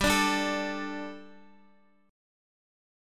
Gsus2 chord